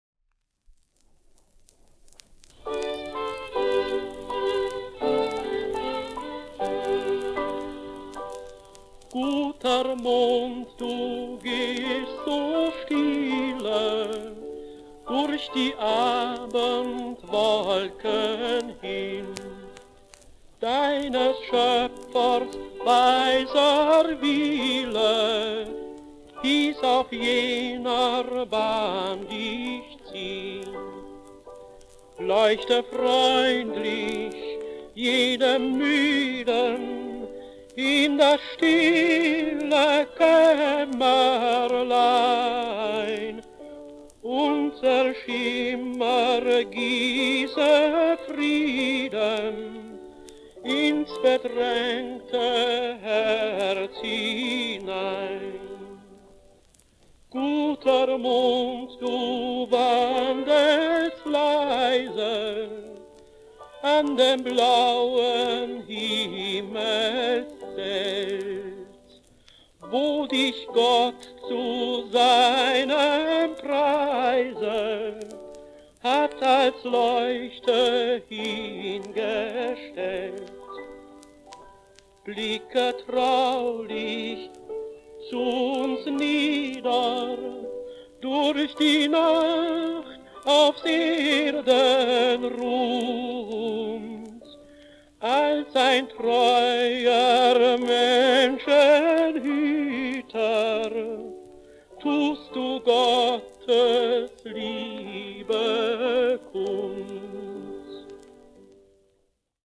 Gute-Nacht- und Schlaflied